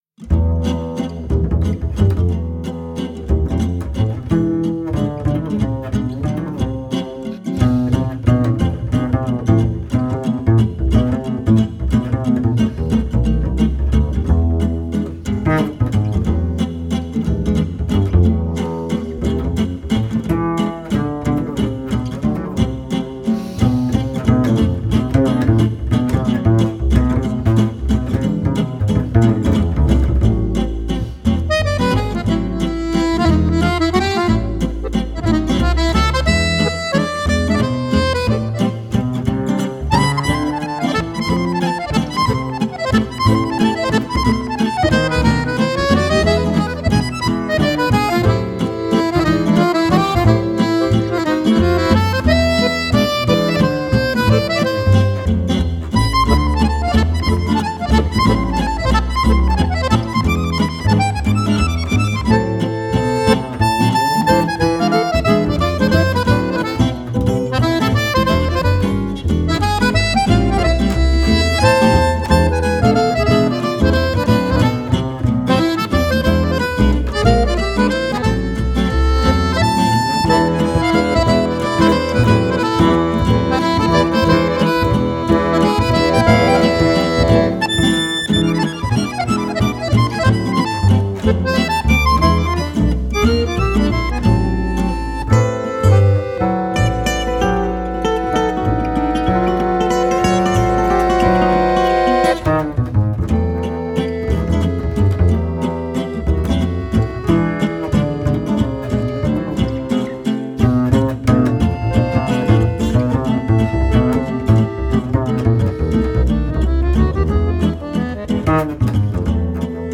La valse est un des joyaux de la couronne du jazz français.
Huit valses jouées par le trio et huit par l'octet.
contrebasse